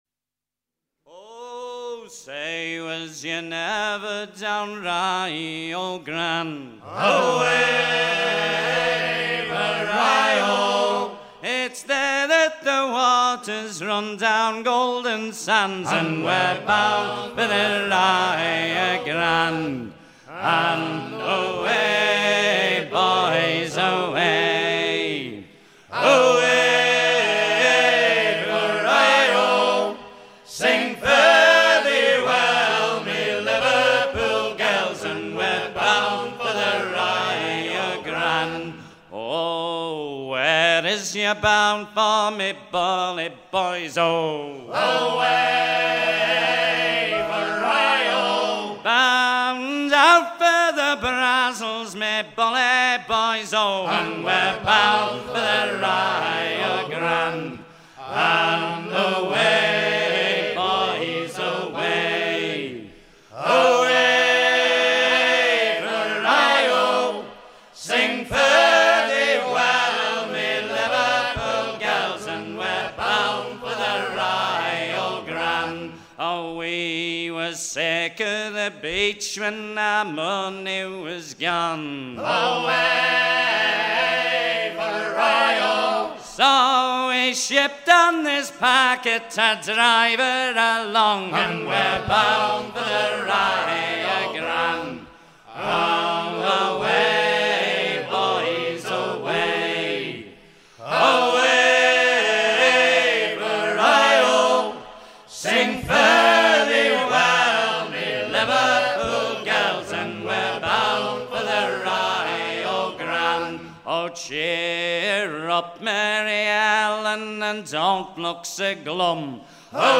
à virer au cabestan
circonstance : maritimes